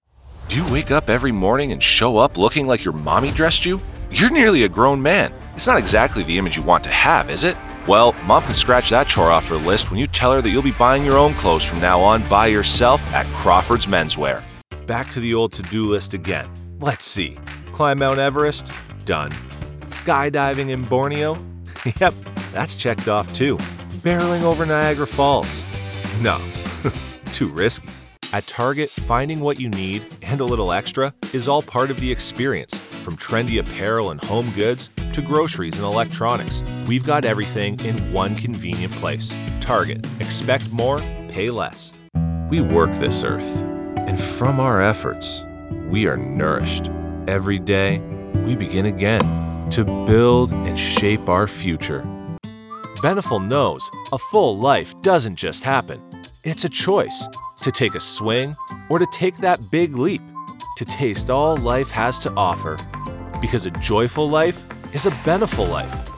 0503Radio_Demo.mp3